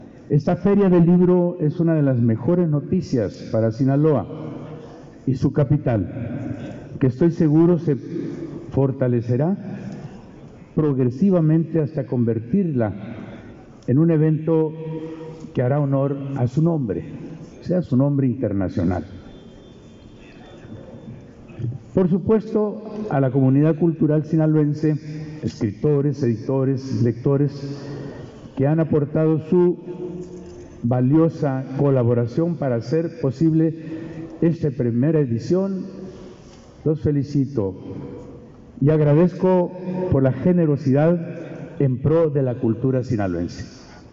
Gobernador Rocha clausura la Feria Internacional del Libro Culiacán 2022
El gobernador Rubén Rocha Moya encabezó la ceremonia de clausura de la Feria Internacional del Libro Culiacán 2022, festival que se lleva a cabo desde el 8 de diciembre y que tendrá como día final el 15 de este mismo mes.